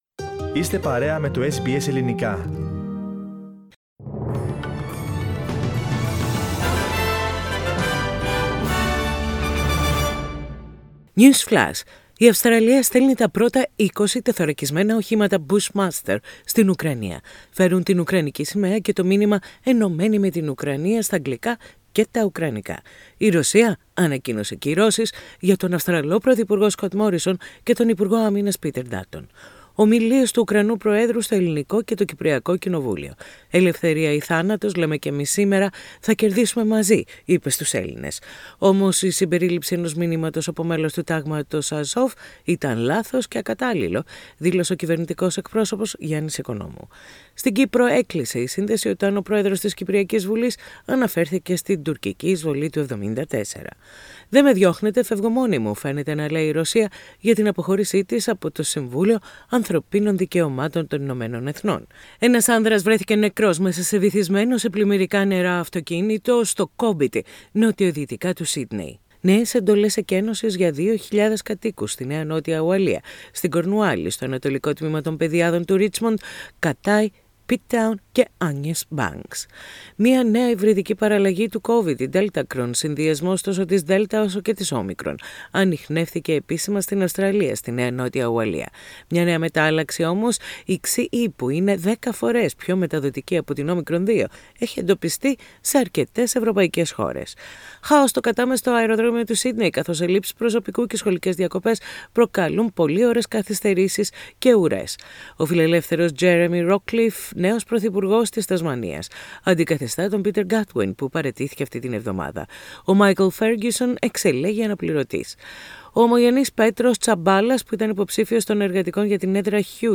News Flash - Σύντομο Δελτίο Ειδήσεων - Παρασκευή 8.4.22